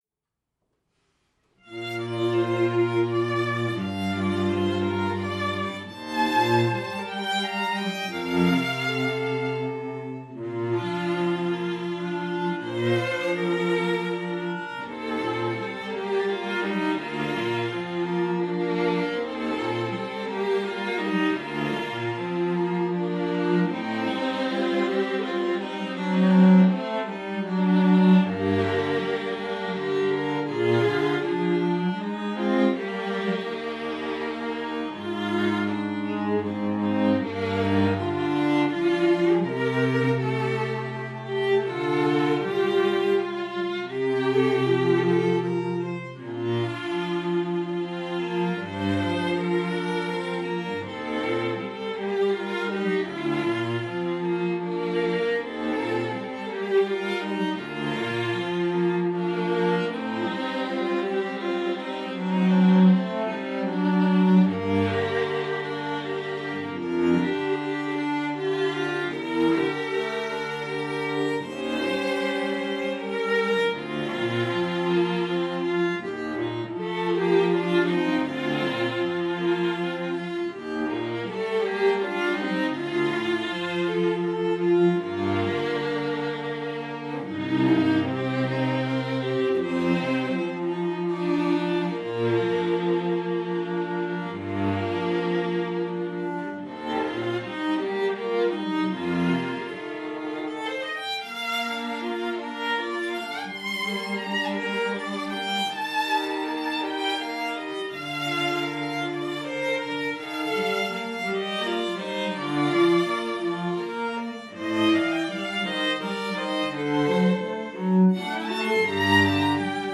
Trio: Violin, Viola, & Cello – Contemporary